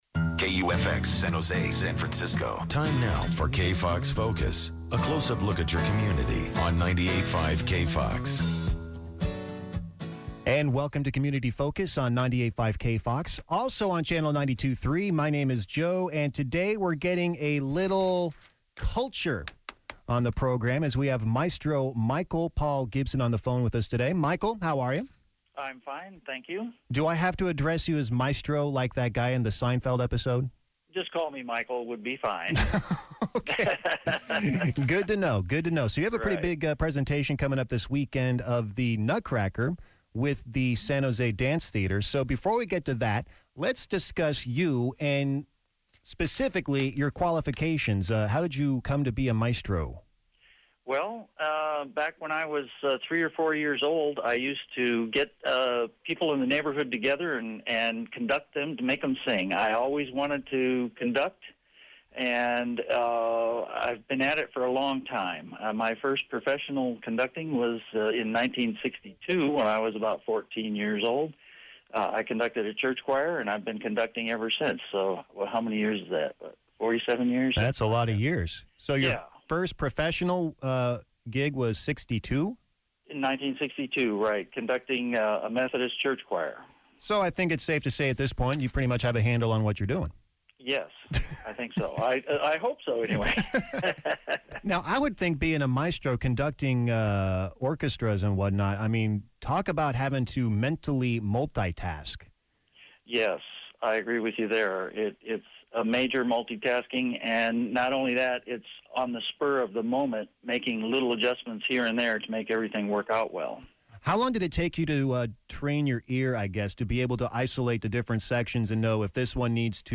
KFOX Radio Interview
KFOX_Interview.mp3